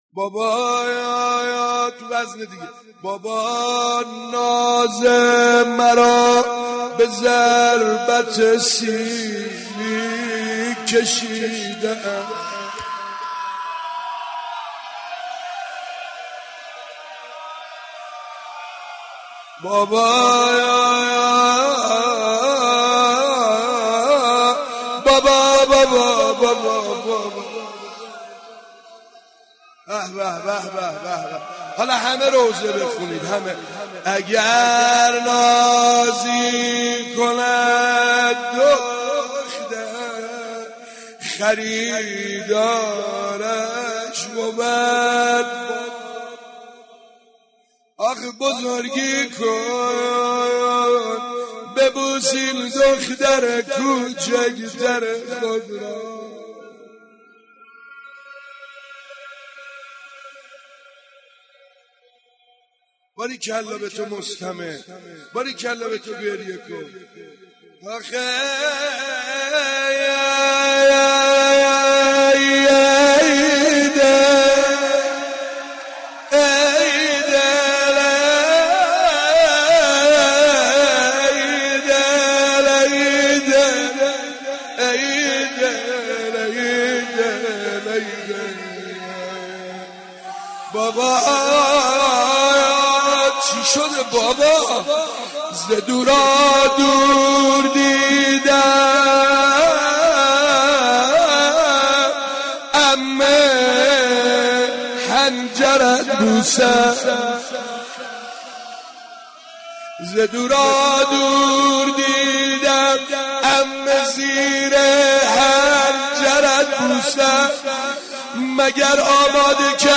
صوت/نوحه زبانحال دختر 3ساله امام‌حسین(ع)
حسینیه 598، نوحه زبانحال دختر سه ساله امام حسین(ع)با صدای سعید حدادیان